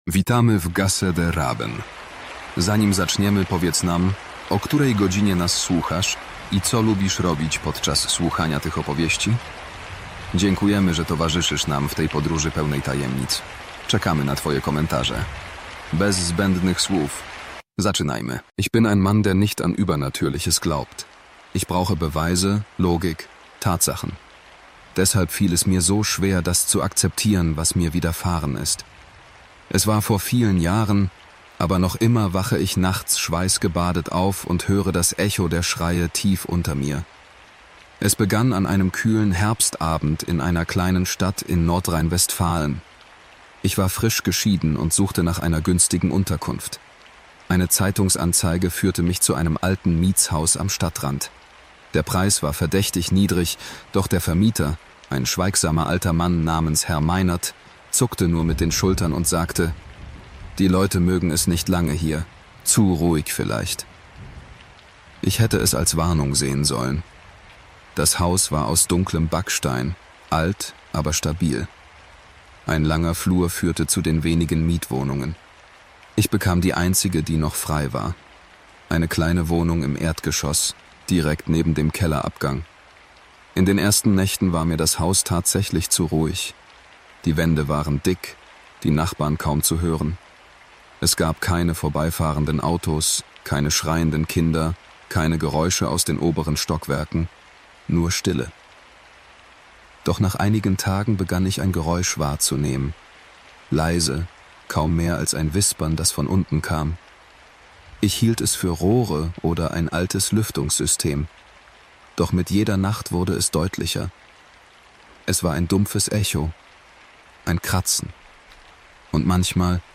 Entspann dich... wenn du kannst: 1 Stunde Grusel mit Regenambiente zum Einschlafen